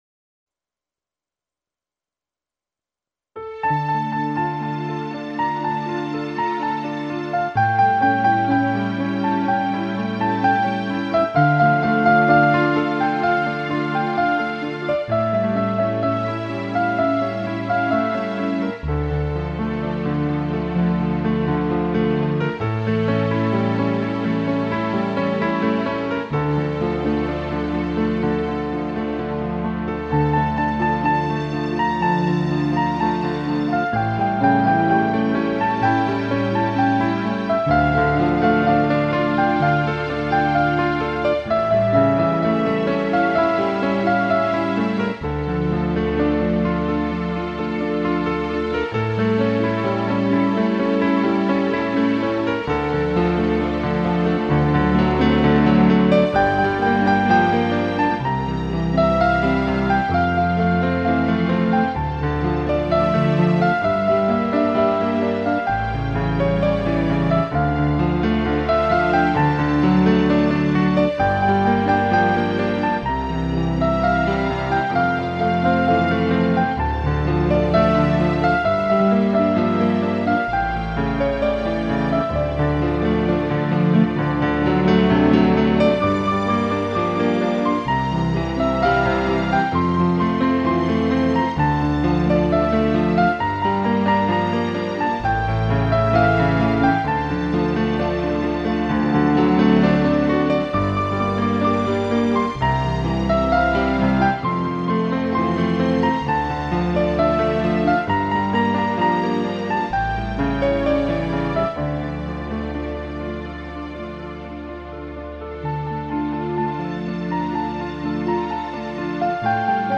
慢慢聽吧，相信大夥會跟我一樣，愛上這樣的琴，這樣用情感去敲擊而出的抒情鋼琴。